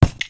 wrench_hit_card2.wav